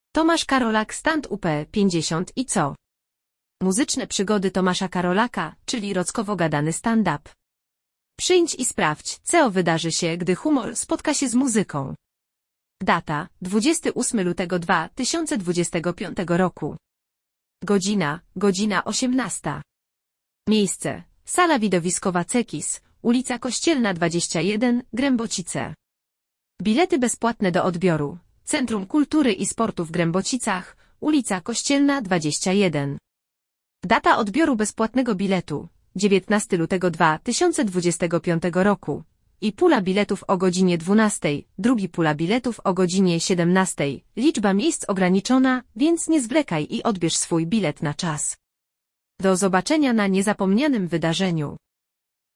Muzyczne przygody Tomasza Karolaka, czyli rockowo-gadany stand-up!